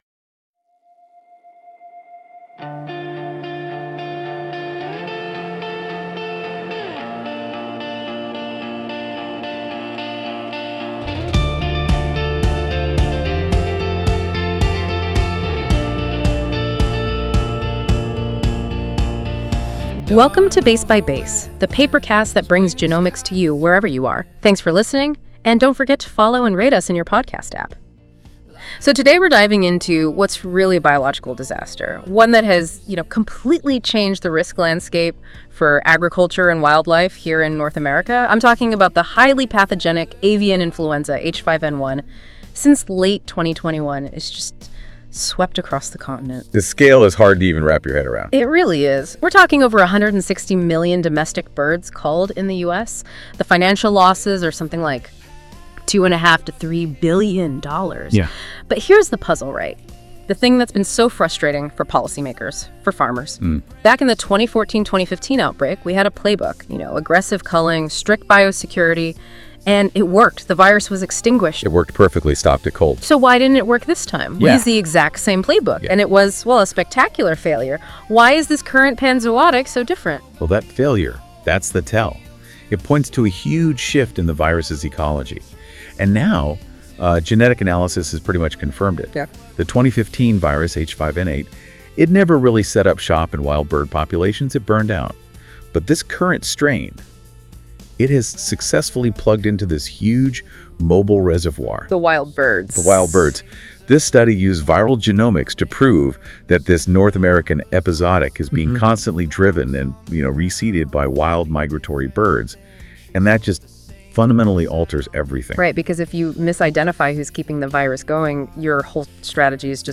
Wild Birds and the North American H5N1 Epizootic Music:Enjoy the music based on this article at the end of the episode.